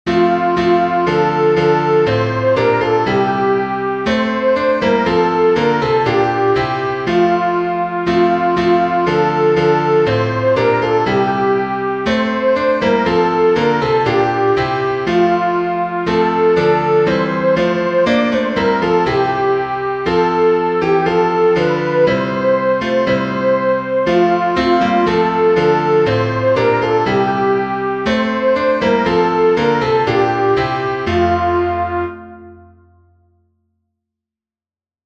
Soprano
llanfair-soprano.mp3